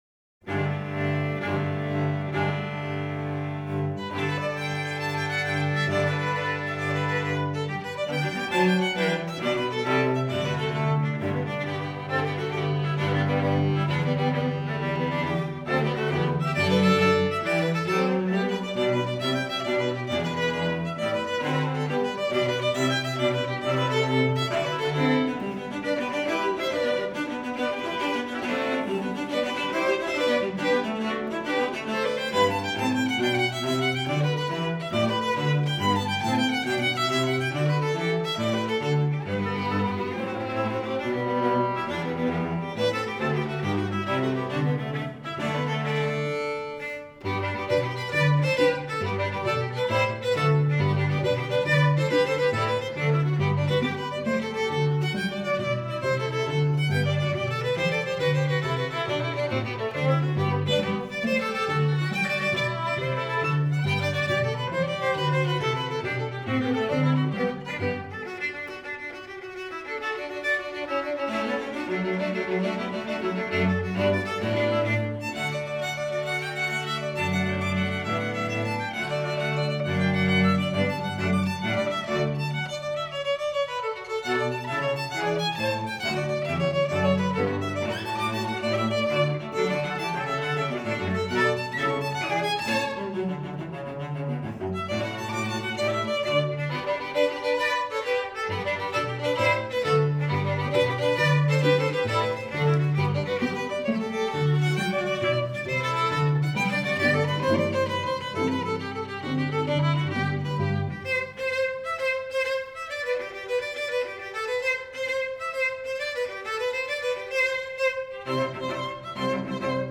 Voicing: String Trio